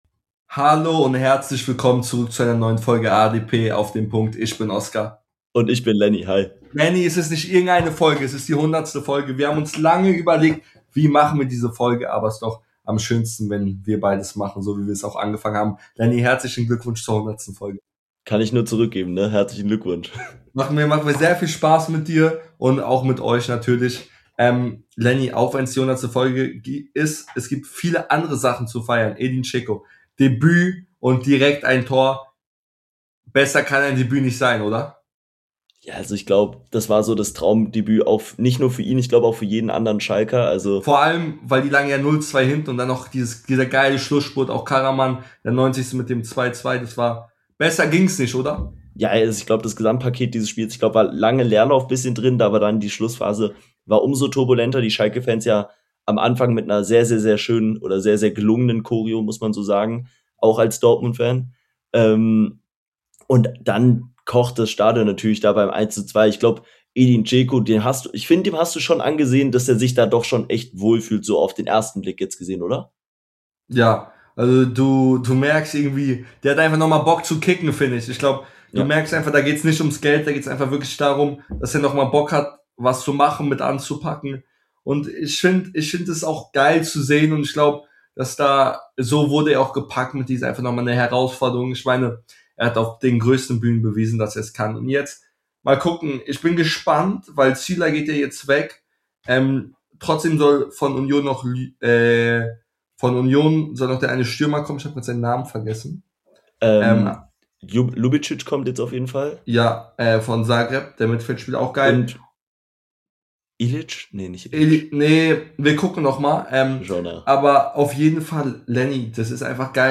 100 Folgen ADP! In der Jubiläumsfolge sprechen die beiden Hosts über den Spieltag , diskutieren über den Abstiegskampf und vieles mehr